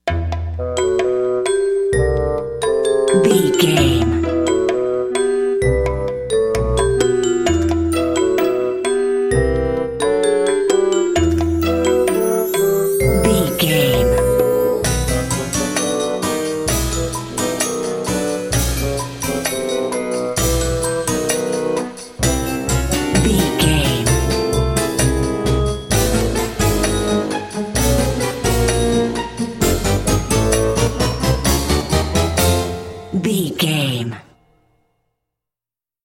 Aeolian/Minor
orchestra
percussion
silly
circus
comical
perky
Light hearted
quirky